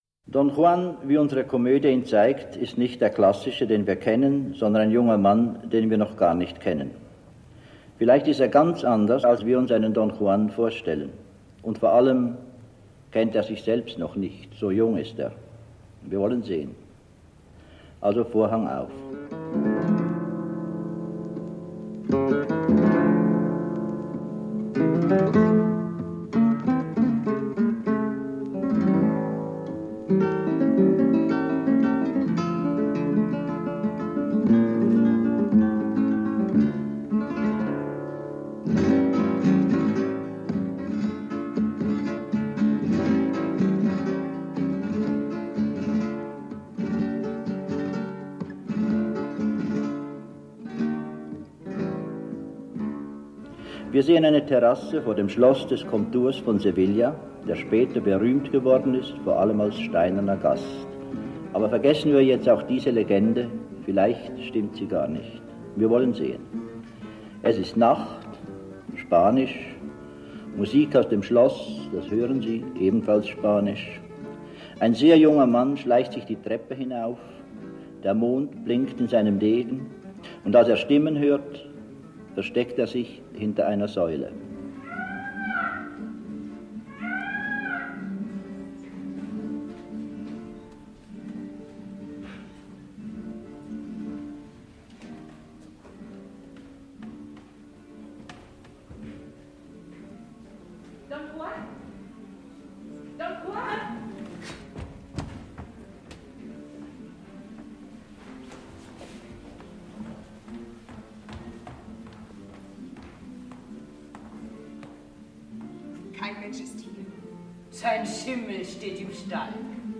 Max Frisch führt durch die Aufführung im Zürcher Schauspielhaus
Aufnahme aus dem Zürcher Schauspielhaus aus dem Jahr 1964 mit Helmuth Lohner, Therese Giehse und Anne-Marie Blanc, kommentiert von Max Frisch selbst.